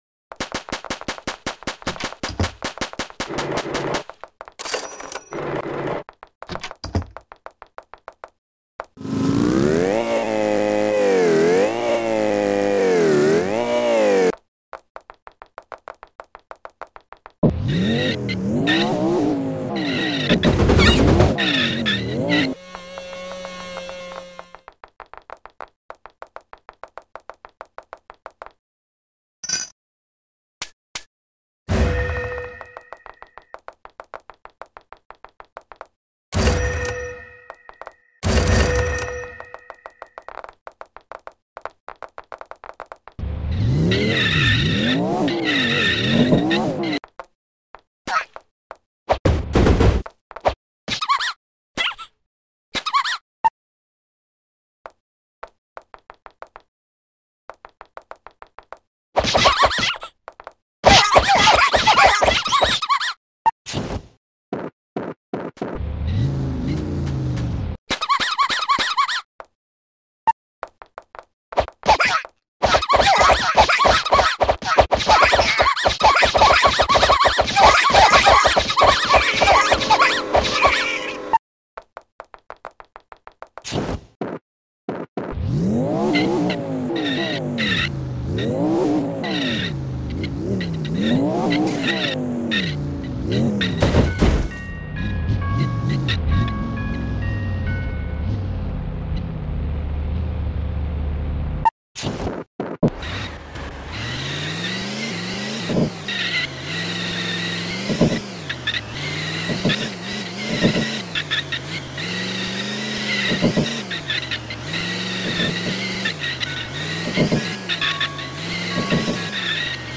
All Police Cars Collection At Sound Effects Free Download